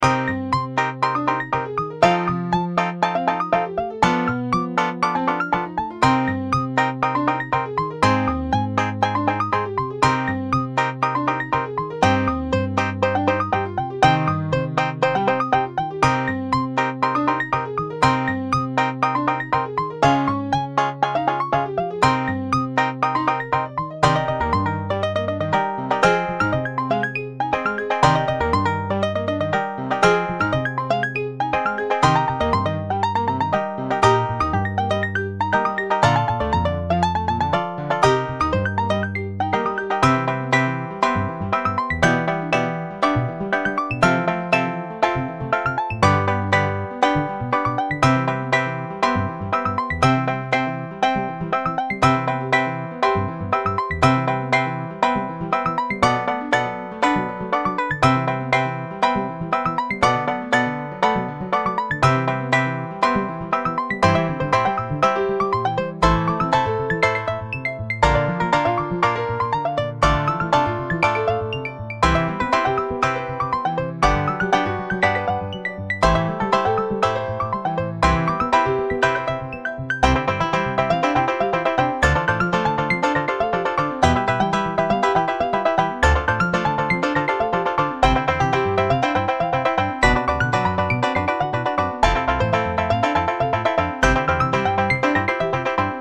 バンジョー、ピアノ、アコースティックベース